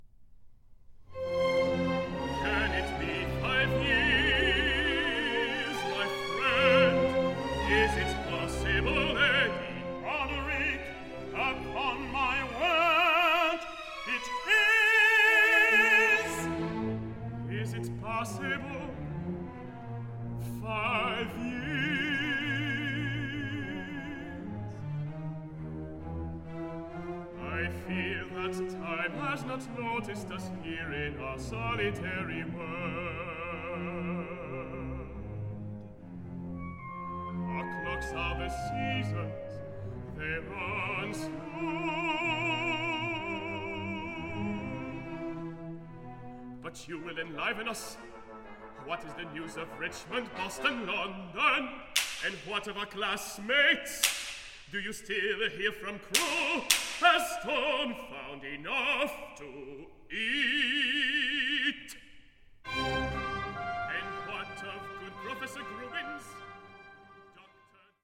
Opera in One Act